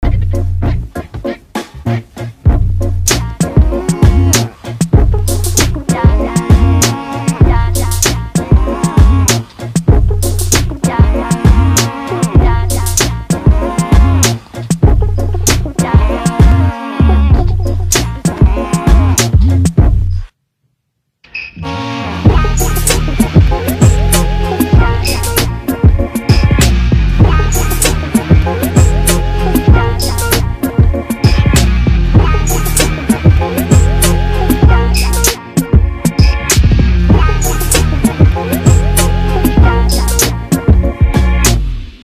Синт или Vocoder?
Подскажите пожалуйста что за звук начинает играть с 3 секунды? Похоже на какое-то мычание обработанное через вокодер, но точно не уверен.